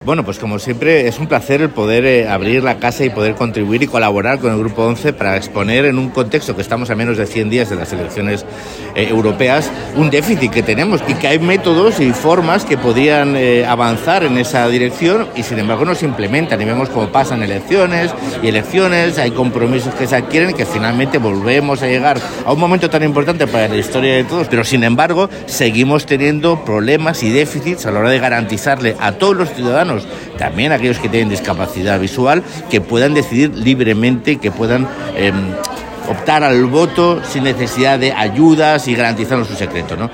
Posteriormente tuvo lugar una mesa redonda en la que intervinieron los eurodiputados